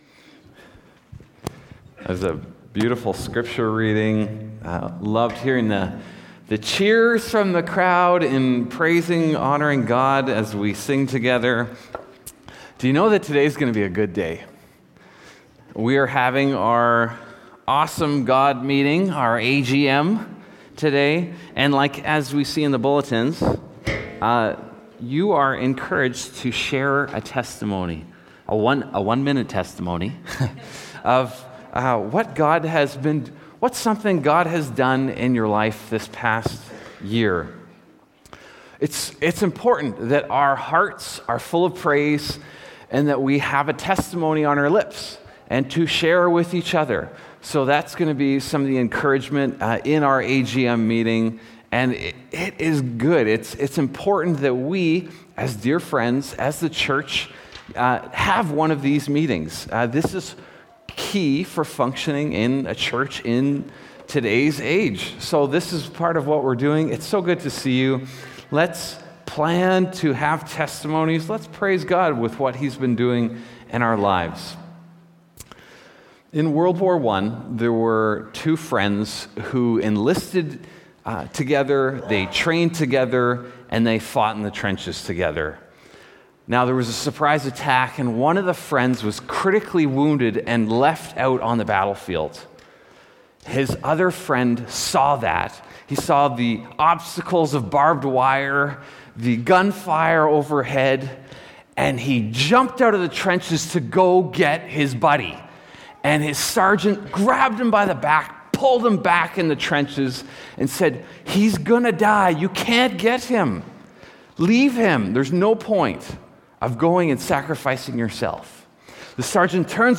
Sermons | Northstar Church